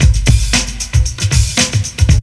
ELECTRO 07-R.wav